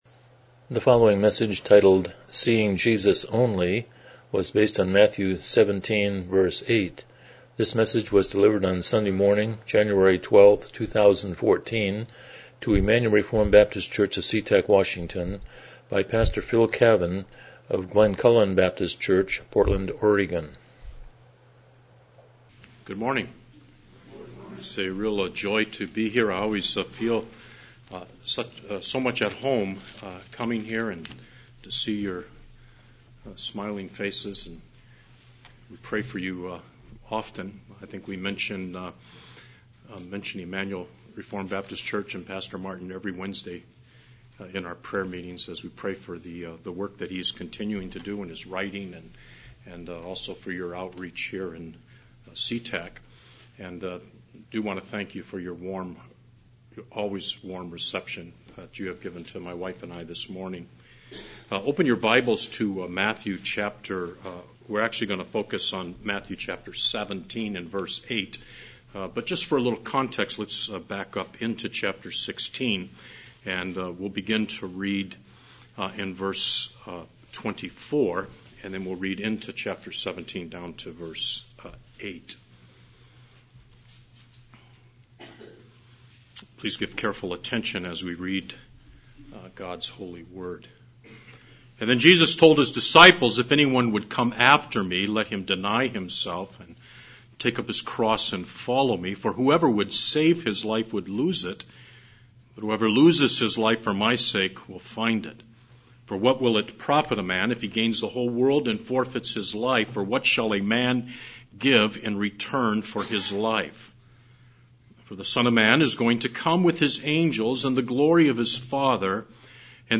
Matthew 17:8 Service Type: Morning Worship « 26 The Sovereignty of God